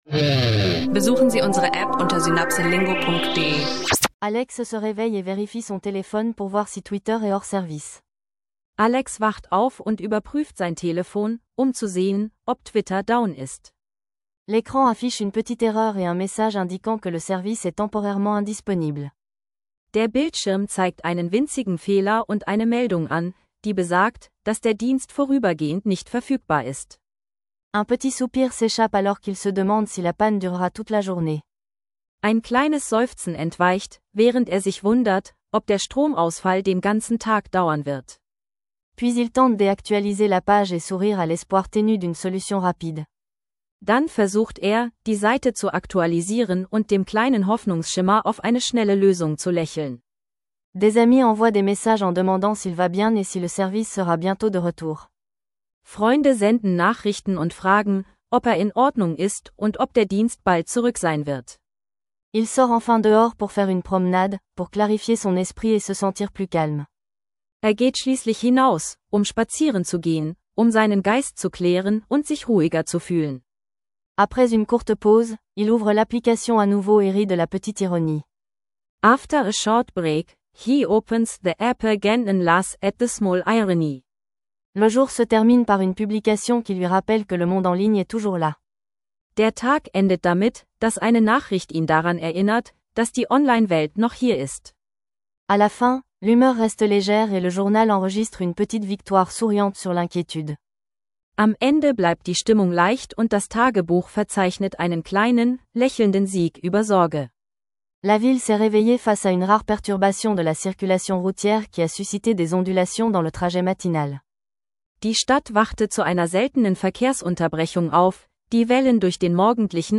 Französisch lernen leicht gemacht: Alltagstaugliche Dialoge & Vokabeln aus Alltagssituationen – jetzt reinhören und mit SynapseLingo verbessern.